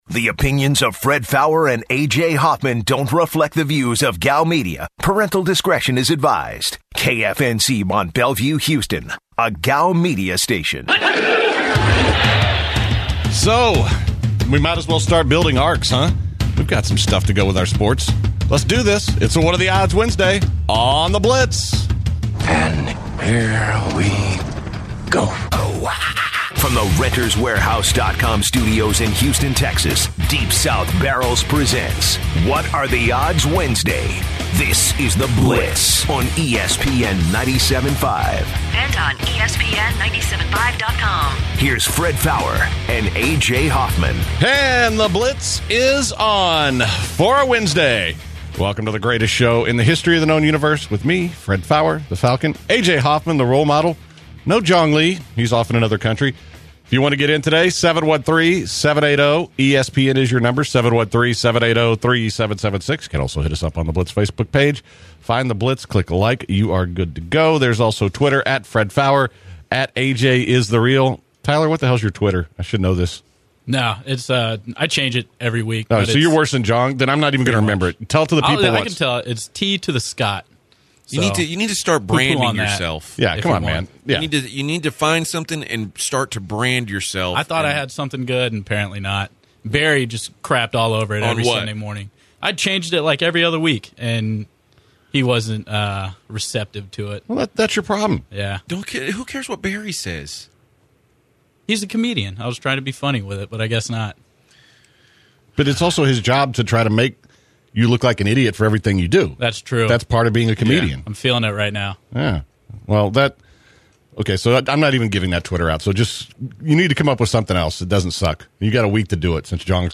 The guys also mention Ken Starr resigning as Chancellor from Baylor University, the Houston Rockets press conference for Head Coach Mike D’Antoni and take calls from the blitzers.